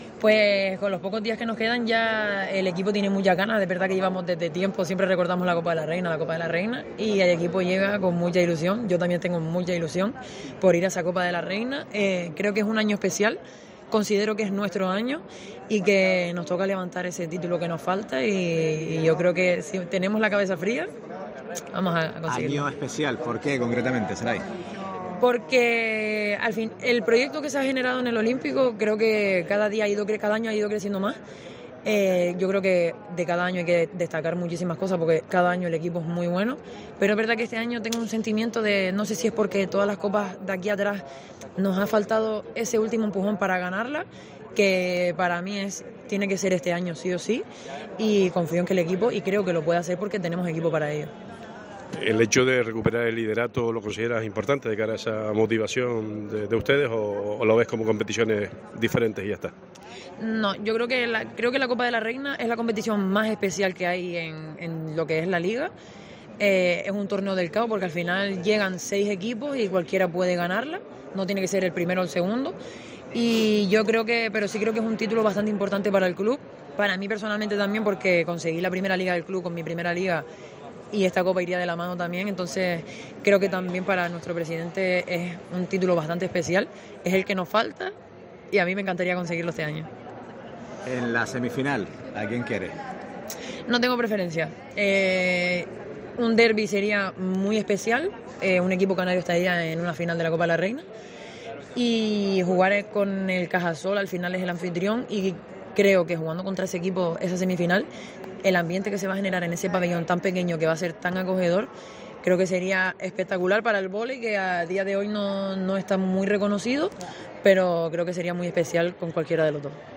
El primer equipo del CV Hidramar Gran Canaria recibió este mediodía la visita de los diferentes medios de comunicación desplazados hasta la pista central del Centro Insular de Deportes.